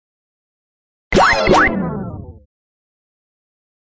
科幻机器的启动杂声
描述：科幻机器的启动杂声。
标签： 机器 向上 杂声 功率
声道立体声